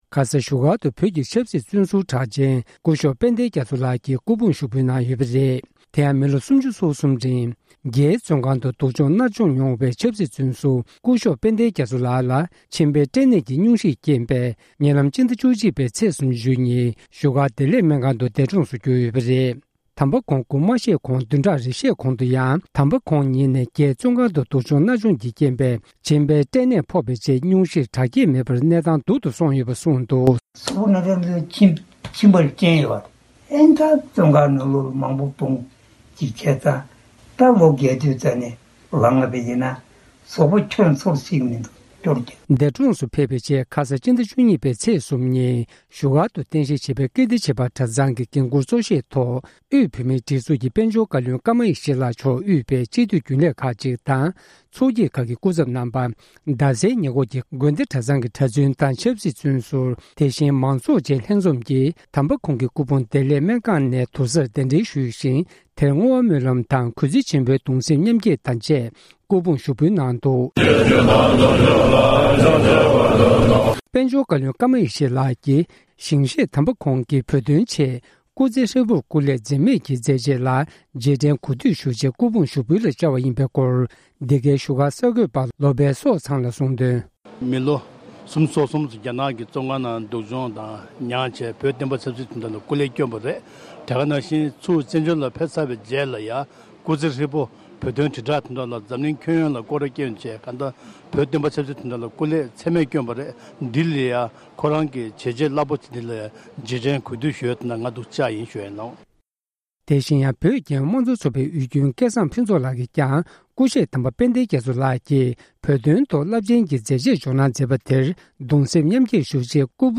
སྐུ་ཞབས་དཔལ་ལྡན་རྒྱ་མཚོ་ལགས་ཀྱི་སྐུ་ཕུང་ཞུགས་འབུལ་གནང་བ། སྒྲ་ལྡན་གསར་འགྱུར།